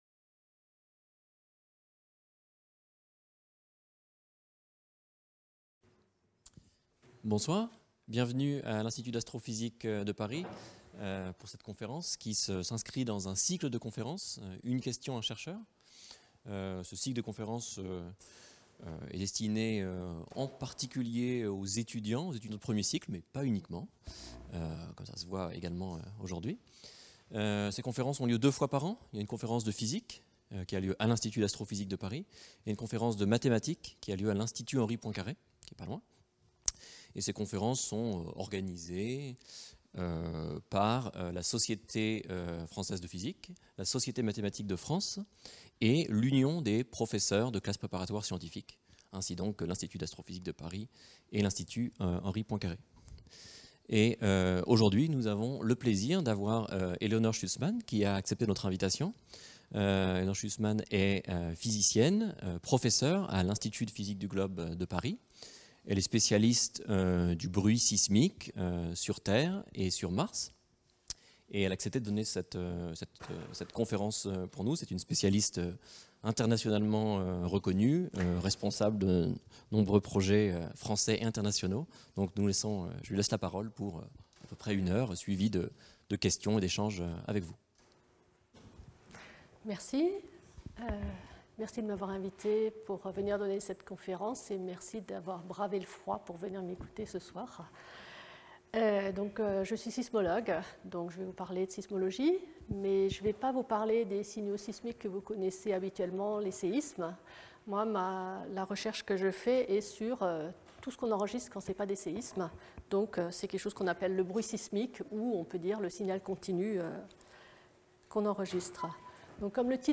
Dans cette conférence, je présenterai les mécanismes qui permettent de générer les ondes qui composent le bruit sismique.